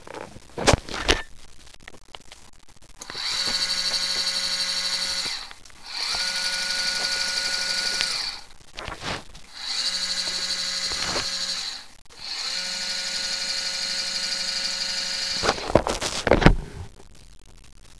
I only had a crappy microphone so crank your sound and igore the white noise at the beginning of most of these.
Here is what it sounds like warm slewing East, West, East, West:
lx_200_warm_East_then_West.wav